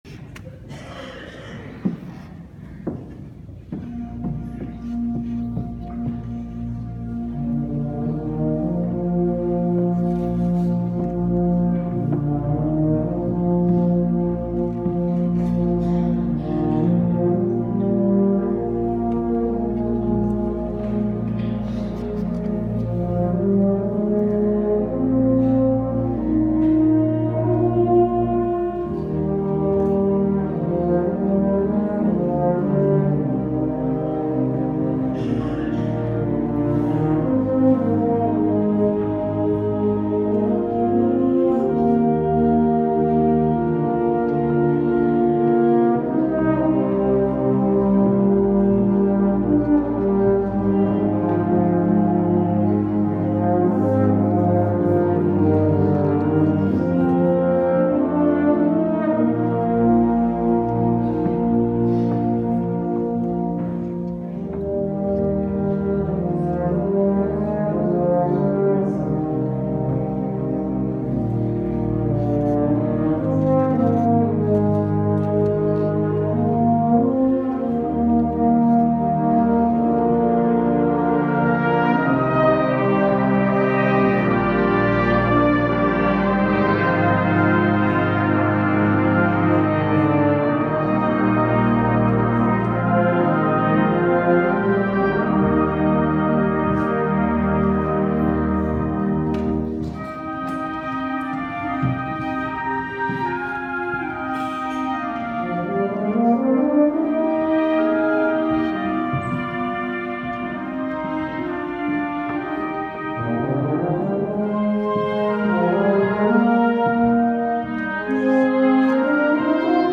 MEDIA – HARMONIE & KLAROENKORPS
Opnames Harmonie Amicitia tijdens St. Ceciliamis 2016 (via Iphone)